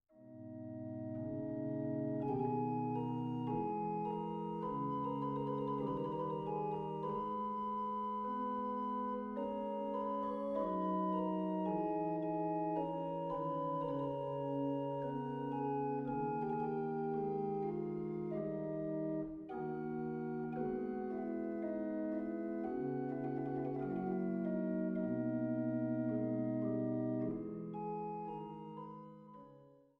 Mezzosopran, Orgel
Bariton, Orgel, Cembalo
Laute
Violoncello, Viola da gamba